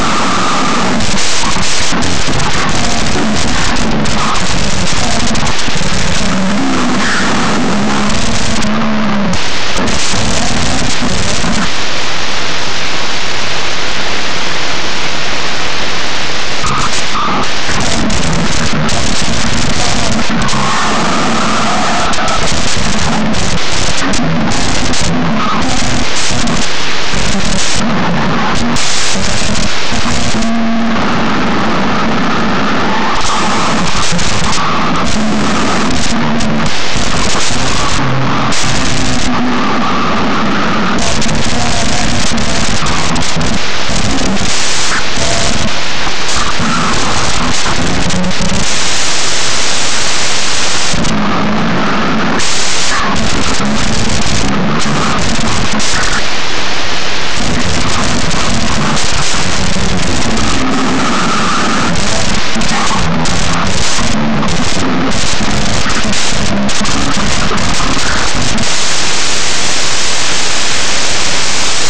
ARGENTINO
• Seseo: provin[s]ias.
• Aspiración y pérdida de -s final de palabra o implosiva de sílaba: va[h]cos, ‘bollos’ [boSo], churra[h]quito, etc.
• Yeísmo con pronunciación fricativa prepalatal sorda [S]: ‘yo’ [So], ‘bollos’ [boSo], ‘llama’ [Sama], ‘rellenan’ [reSenan], ‘cuchillo’ [kutSiSo], ‘lleno’ [Seno].
• Pérdida de /d/ intervocálica: [to] ‘todo’.